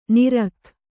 ♪ nirakh